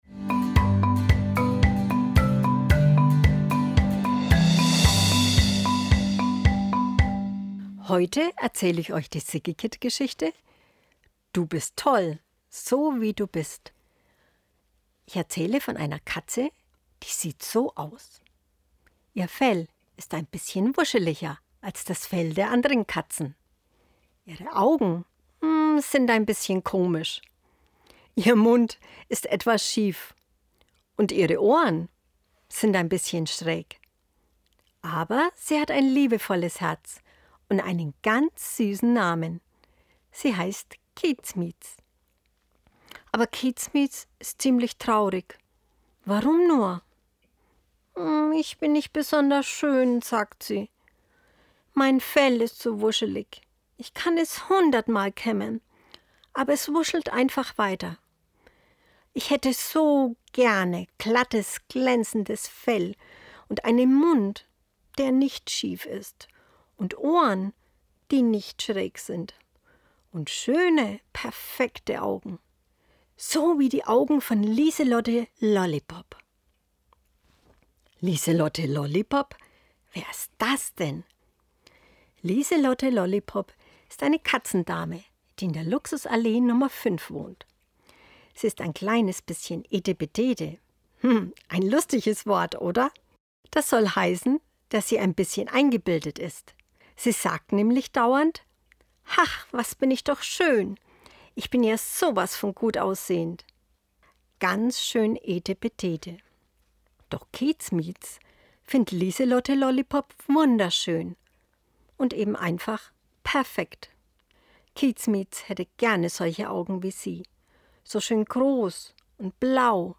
August 2020 Kinderblog Vorlesegeschichten Unsere Kinder-Geschichte handelt von einer ganz wundervollen Katze.